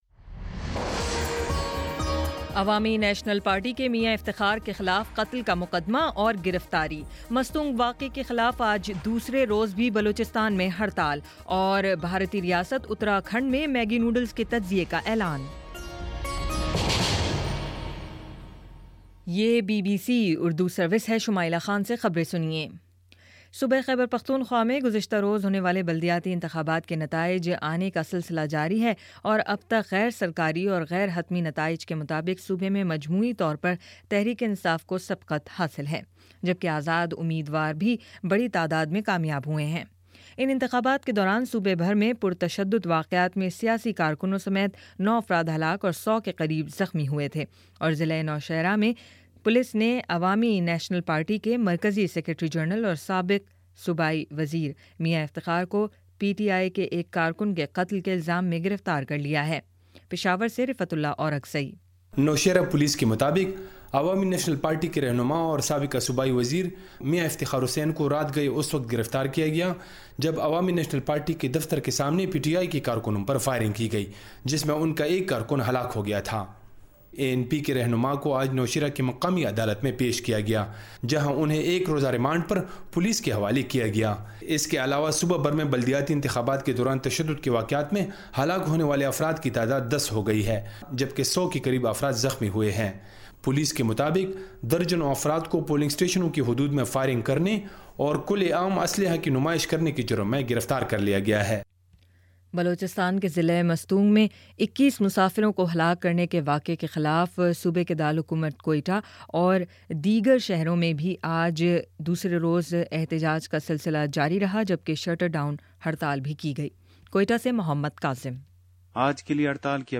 مئی 31: شام پانچ بجے کا نیوز بُلیٹن